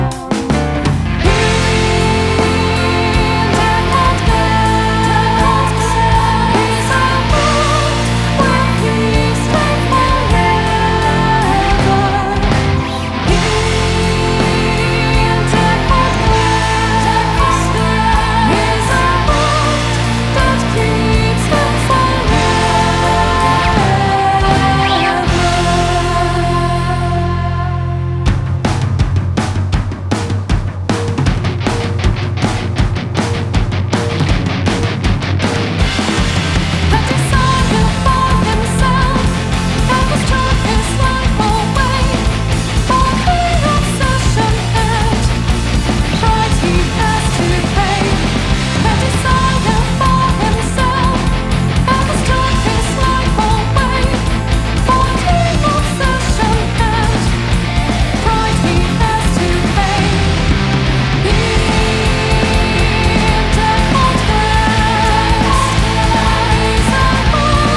Guitars
Keyboard
Drums